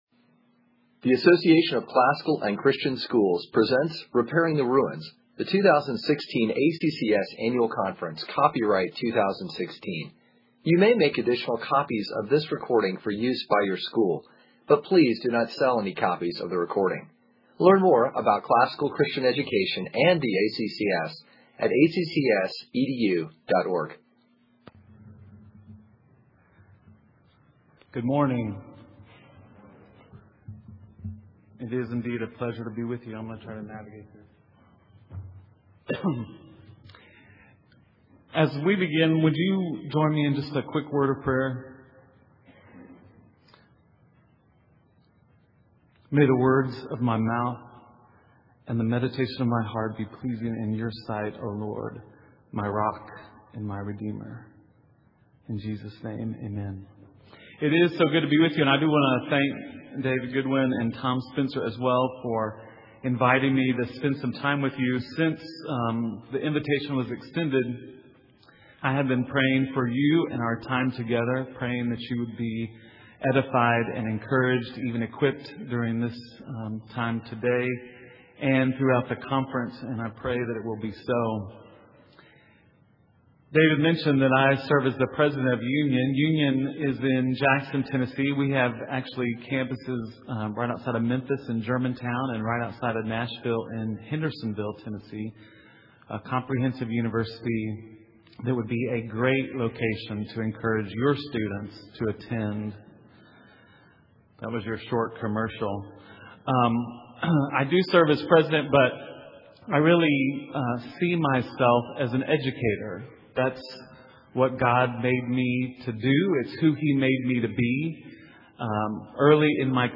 2016 Plenary Talk | 46:44:00 | All Grade Levels, Culture & Faith
Additional Materials The Association of Classical & Christian Schools presents Repairing the Ruins, the ACCS annual conference, copyright ACCS.